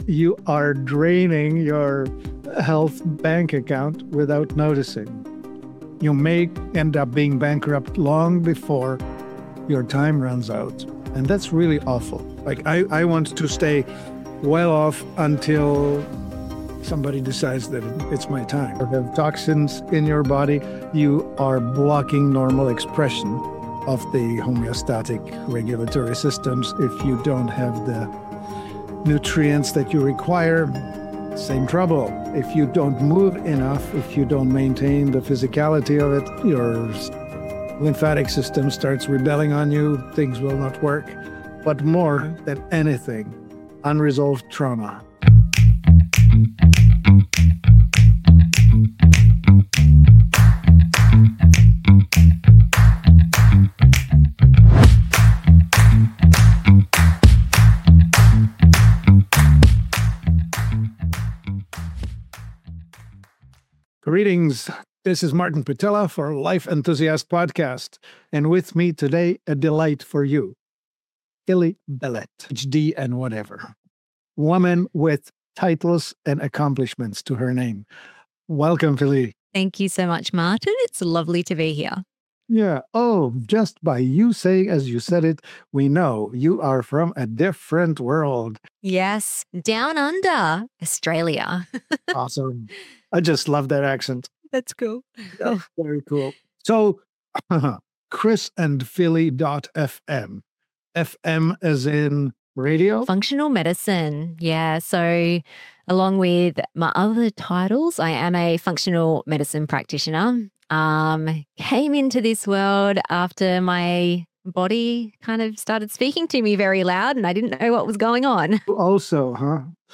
This conversation explores the hidden physiology, unconscious patterns, and environmental stressors that drain your ‘health bank account’ and how to restore it.
This conversation moves beyond the surface-level wellness tips and into the real work: addressing trauma, rebuilding resilience, and creating an environment where true healing can finally take place.